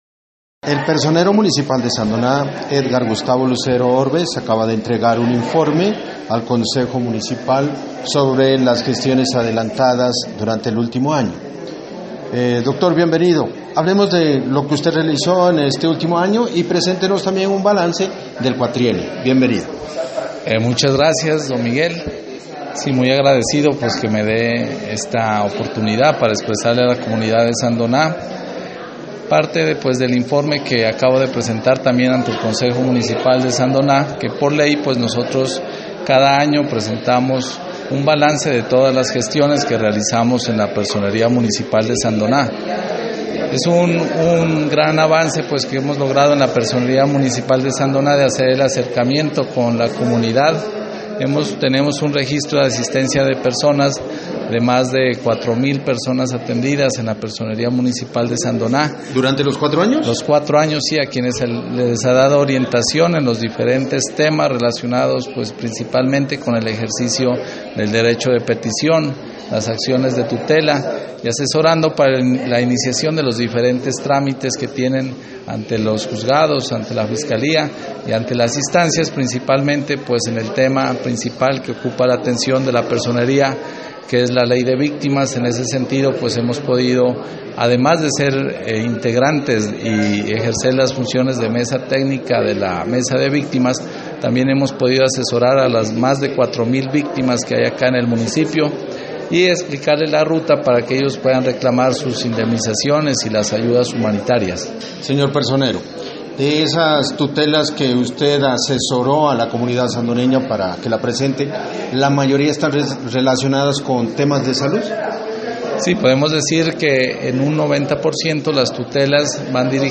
Al final de su intervención dialogamos con el personero de Sandoná, que este sábado termina su período de cuatro años: